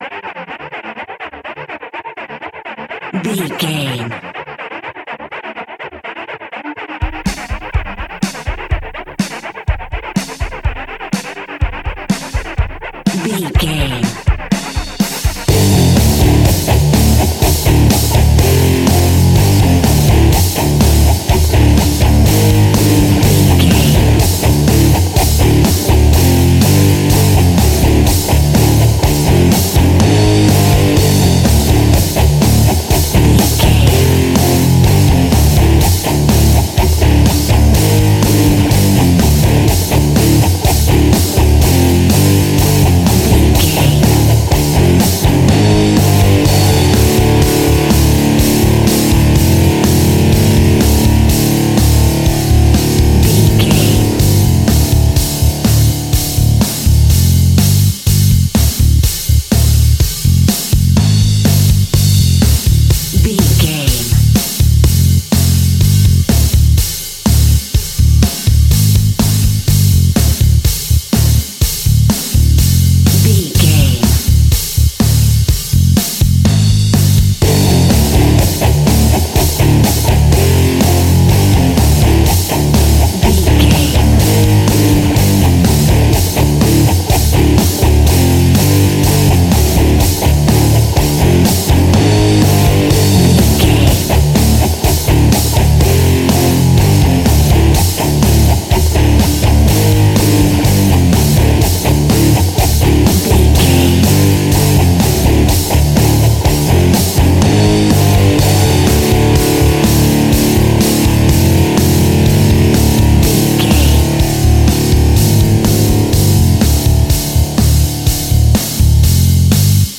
Ionian/Major
energetic
driving
heavy
aggressive
electric guitar
bass guitar
drums
electric organ
hard rock
heavy metal
distortion
heavy drums
distorted guitars
hammond organ